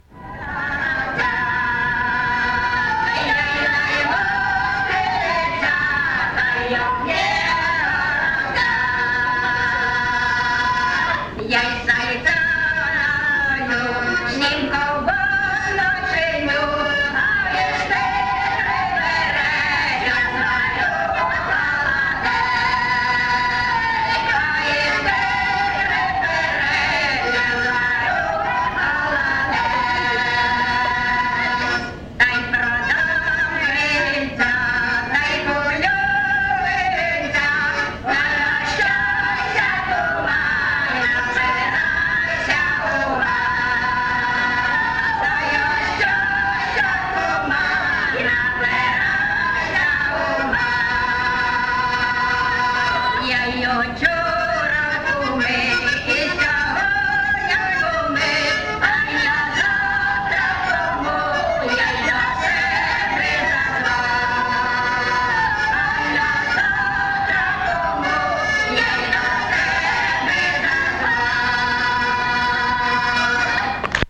ЖанрЖартівливі
Місце записус. Олександрівка, Валківський район, Харківська обл., Україна, Слобожанщина